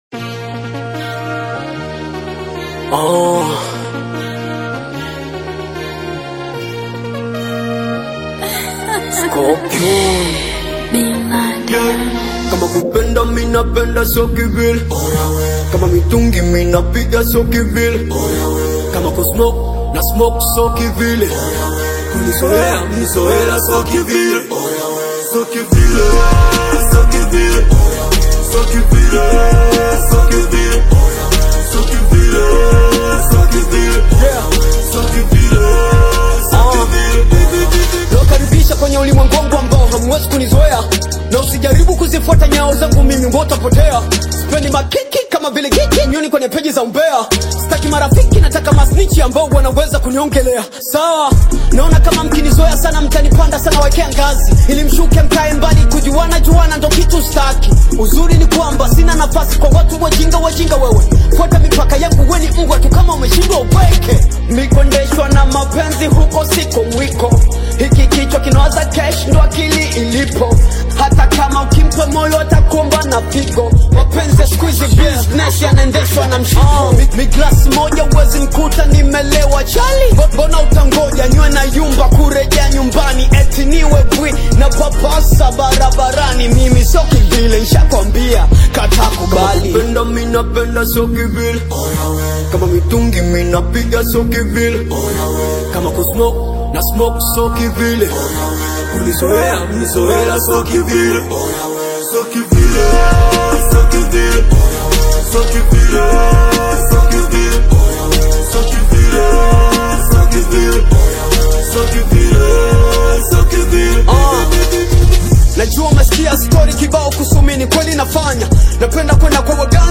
Genre: Afro-Beats